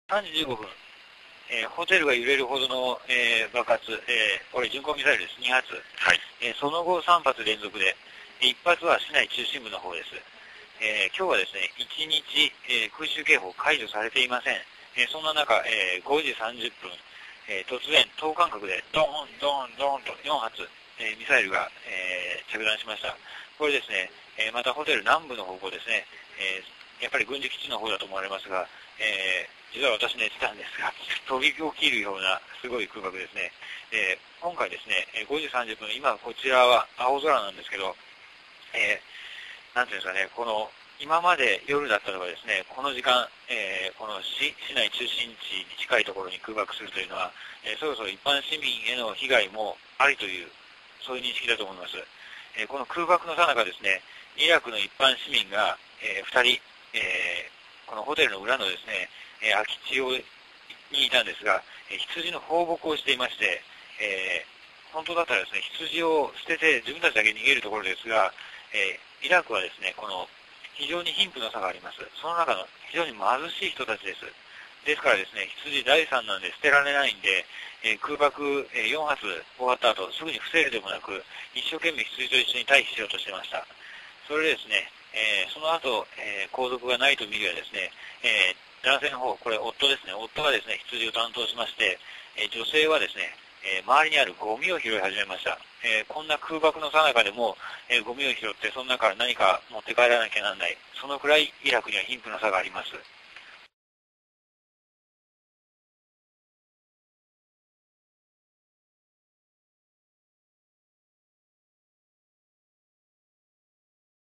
音声リポート